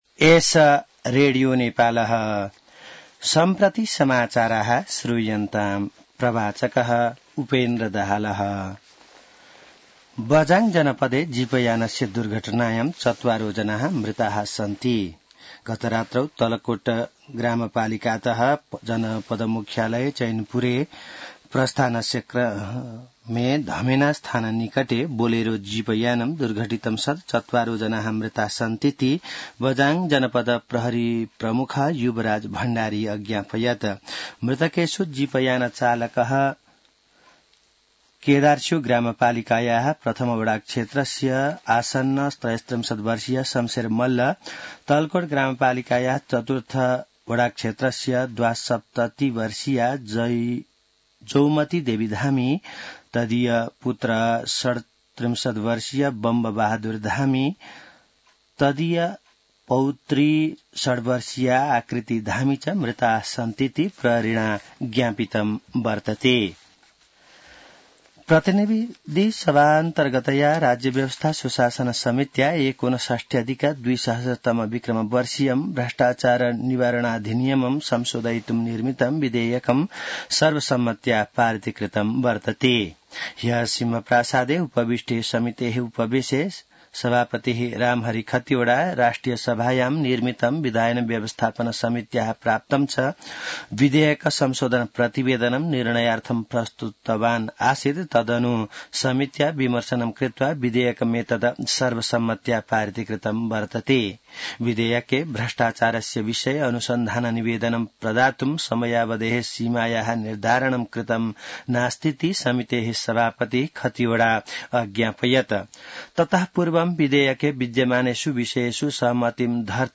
संस्कृत समाचार : २० पुष , २०८१